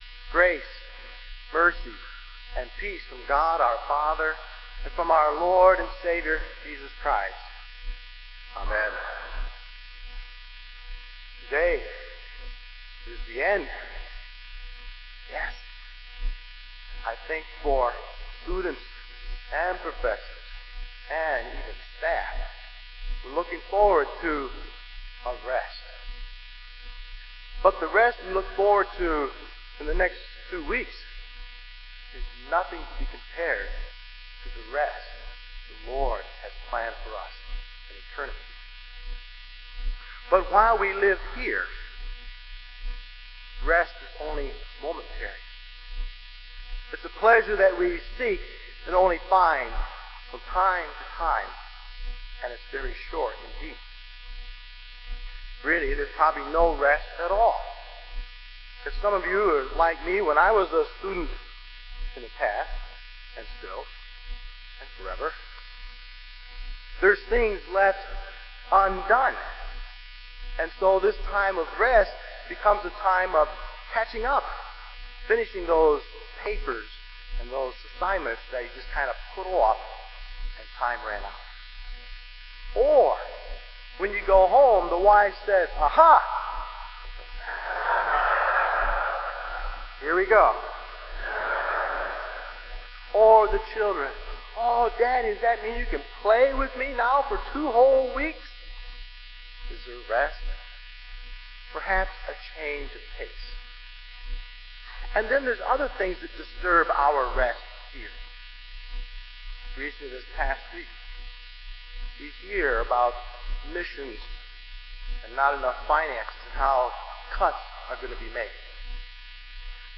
Kramer Chapel Sermon - November 15, 2002